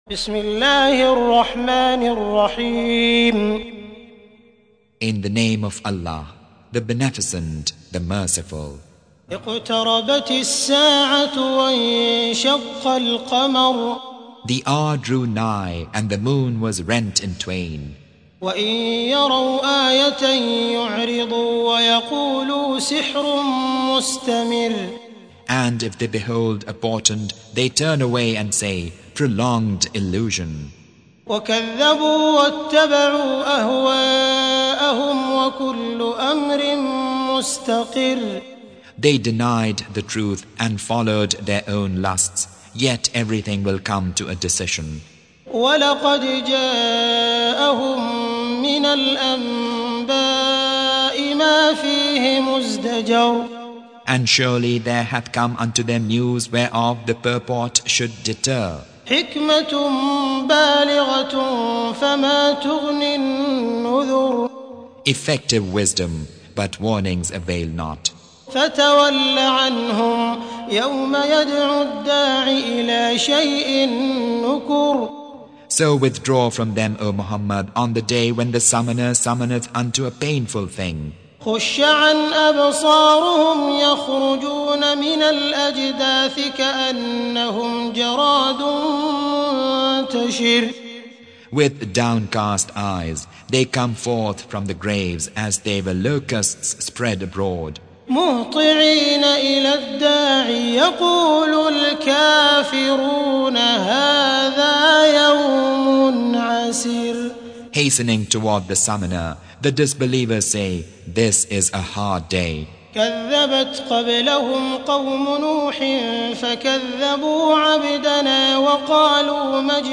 Surah Repeating تكرار السورة Download Surah حمّل السورة Reciting Mutarjamah Translation Audio for 54. Surah Al-Qamar سورة القمر N.B *Surah Includes Al-Basmalah Reciters Sequents تتابع التلاوات Reciters Repeats تكرار التلاوات